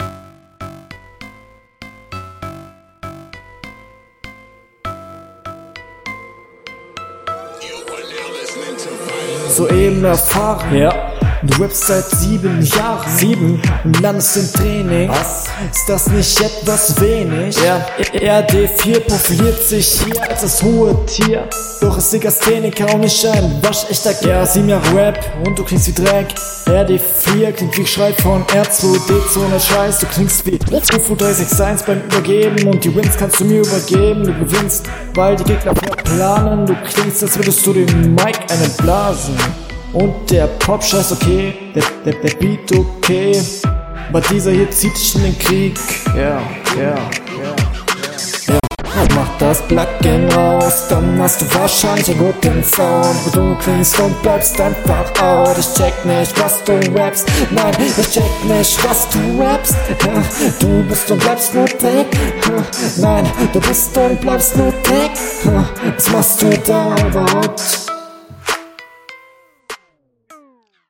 Das mit dem Hall fällt hier noch 100 mal mehr auf.
Du greifst mir ein bisschen zu viel in die Effektkiste.. total wirre Runde.